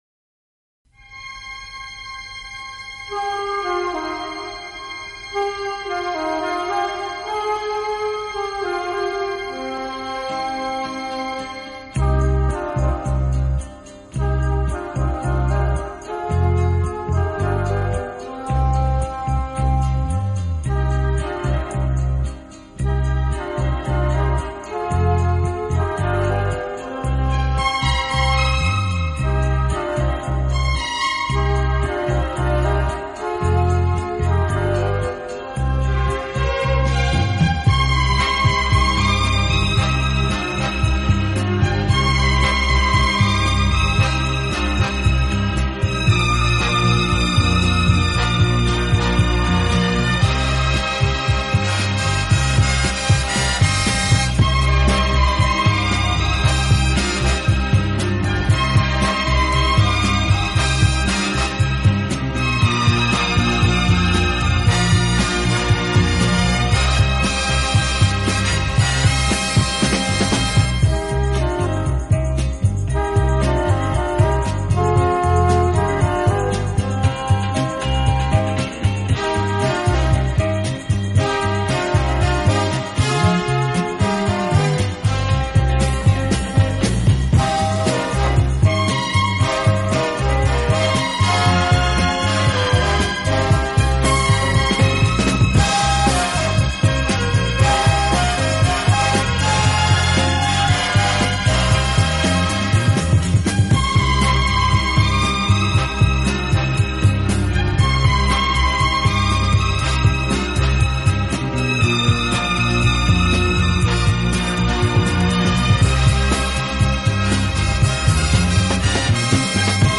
体，曲风浪漫、优雅，令人聆听時如感轻风拂面，丝丝柔情触动心扉，充分领略